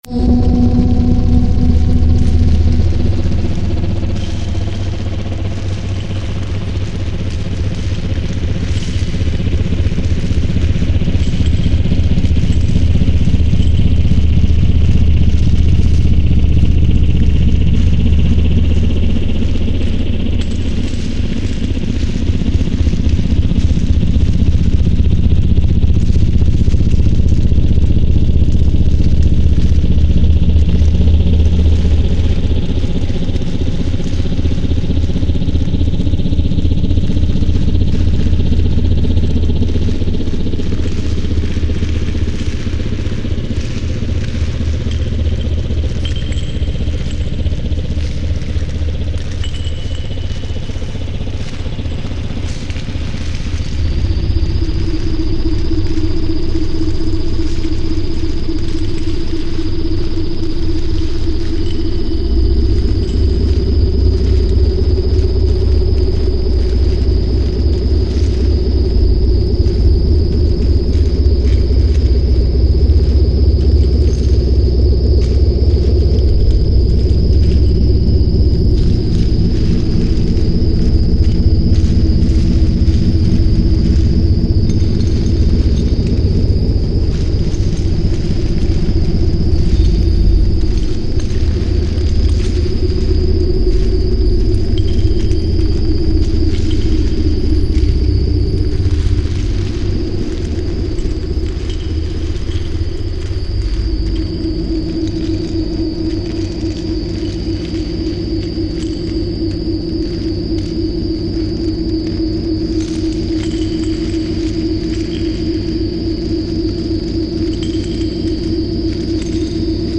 File under: Industrial / Experimental